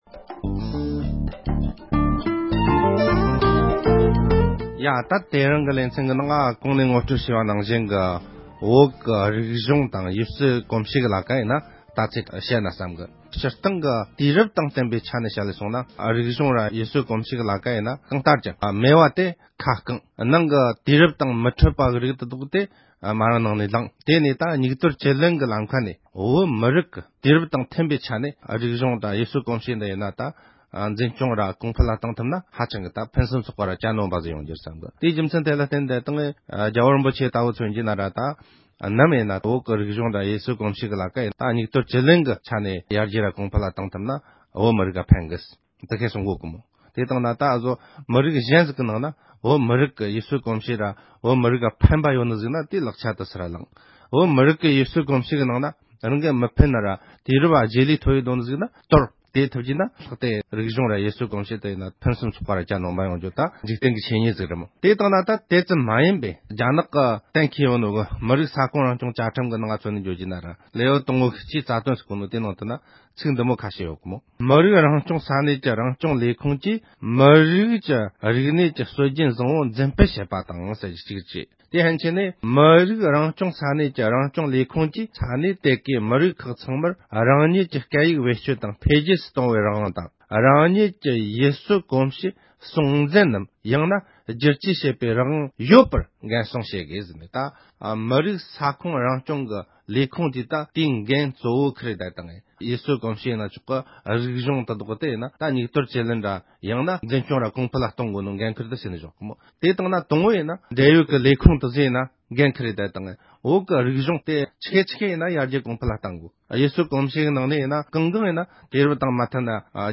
བོད་ཀྱི་རིག་གཞུང་སྲུང་སྐྱོབ་ཀྱི་ཐོབ་ཐང་དང་བྱེད་ཐབས་ཀྱི་སྐོར་དཔྱད་གཏམ།